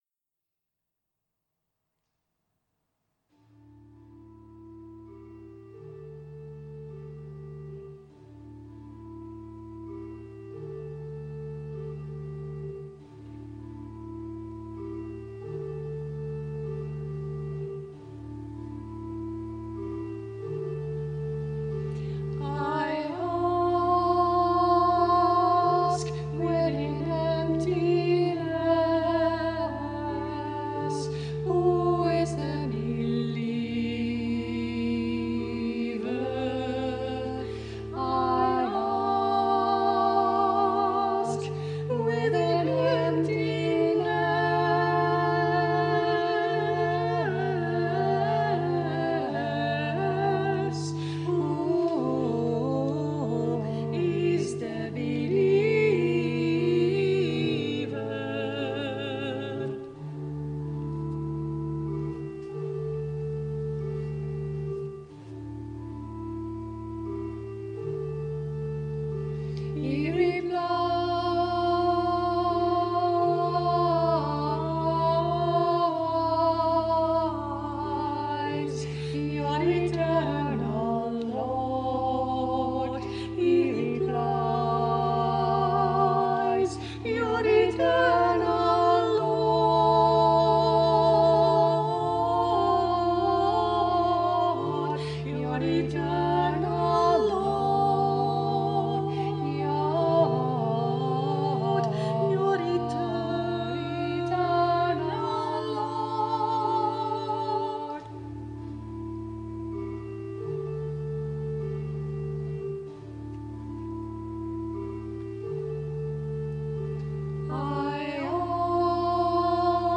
soprano
mezzo-soprano
organ accompaniment
It is a long and complex choral piece for soprano and alto
are two sopranos singing it as a duet and the low notes in the alto part are especially hard work for a soprano.
On October 22, 2017, it was recorded in a local church.